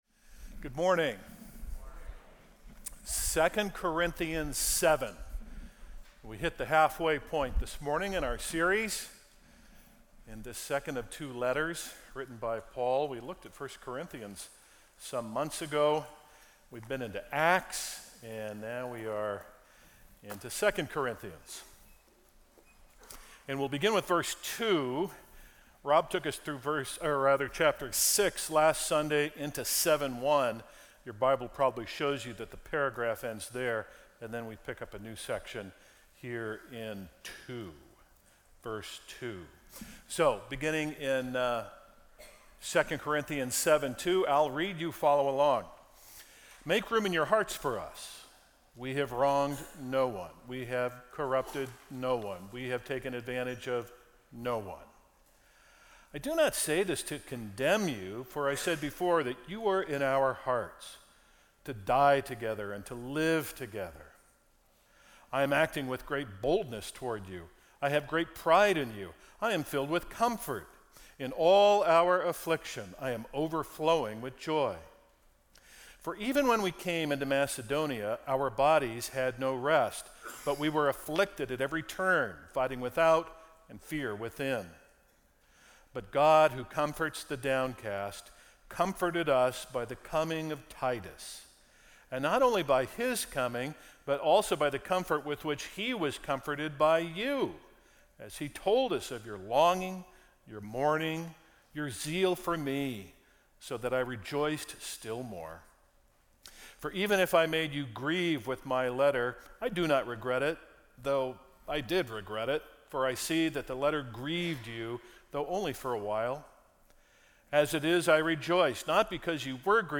Sermons - Grace Evangelical Free Church